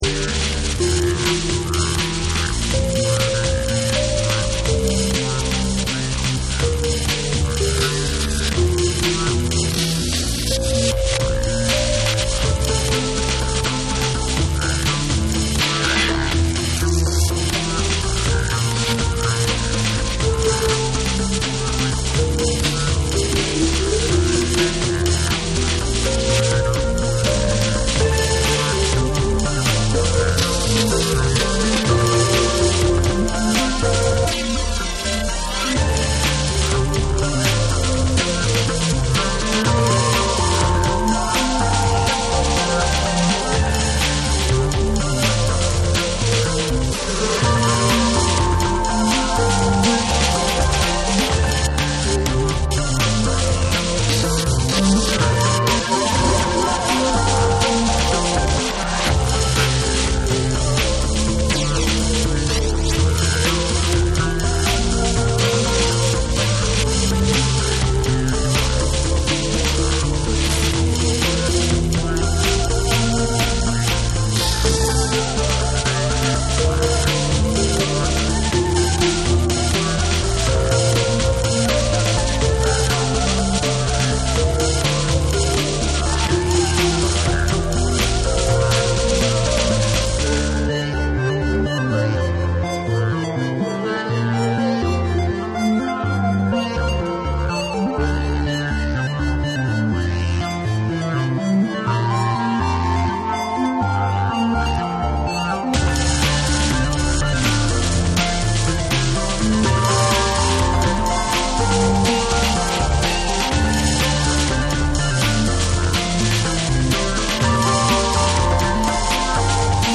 ノイジーに叩き打つリズムが破壊力抜群なドラムンベース
JUNGLE & DRUM'N BASS